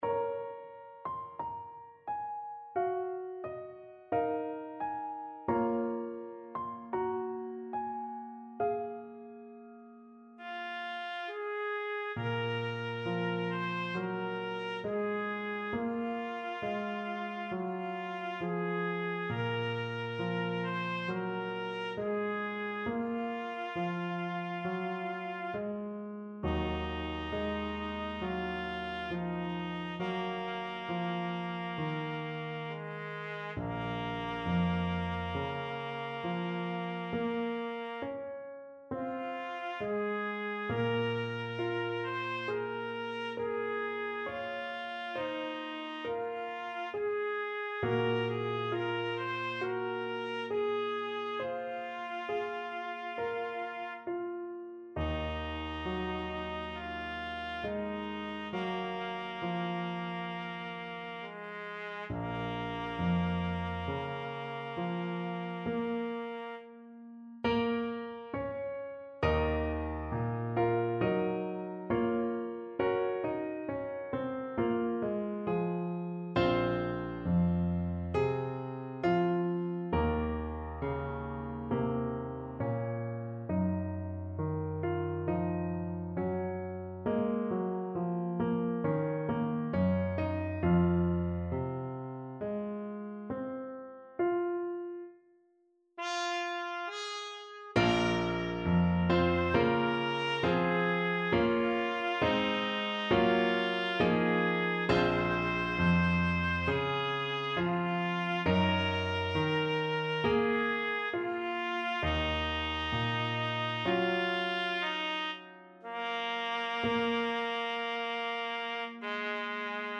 Voicing: Trumpet and Piano